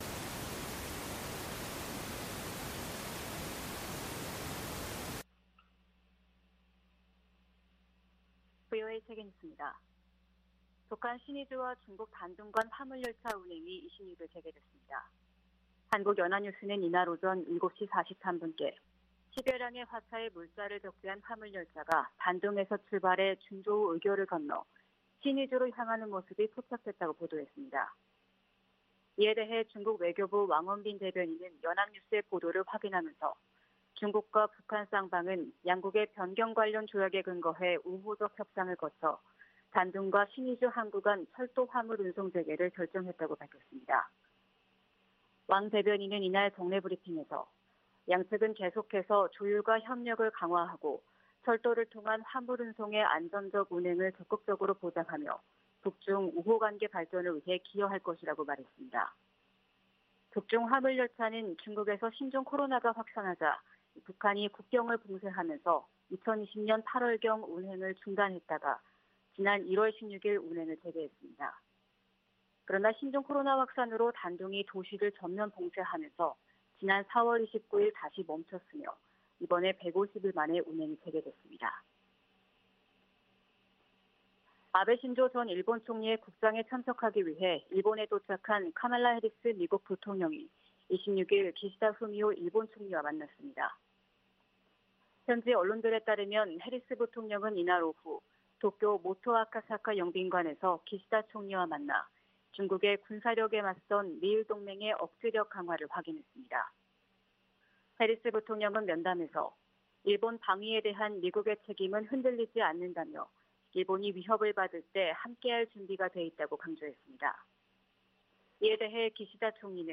VOA 한국어 '출발 뉴스 쇼', 2022년 9월 27일 방송입니다. 한국 정부가 북한의 탄도미사일 도발을 규탄했습니다. 미 국무부도 북한 탄도미사일 발사를 비판하며 유엔 안보리 결의 위반임을 강조했습니다. 북한 신의주와 중국 단둥을 오가는 화물열차가 150일 만에 운행을 재개했습니다.